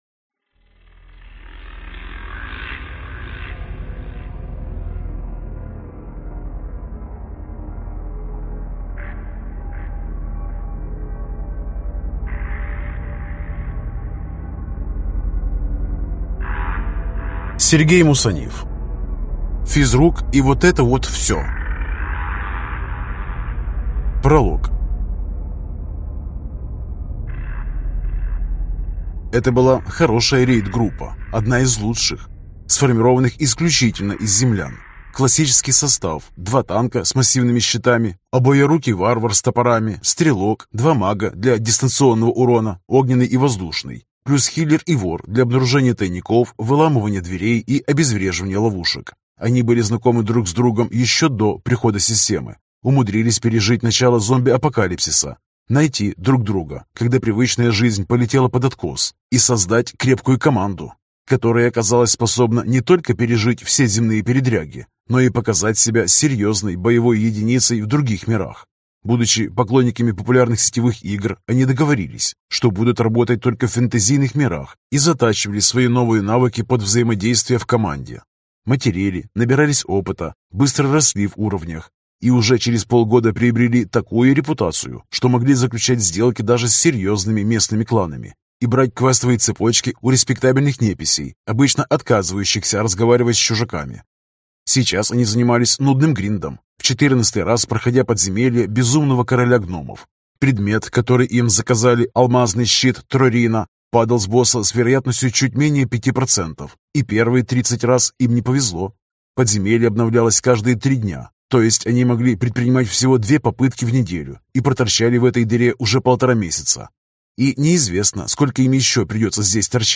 Аудиокнига Физрук и вот это вот всё. Книга 5 | Библиотека аудиокниг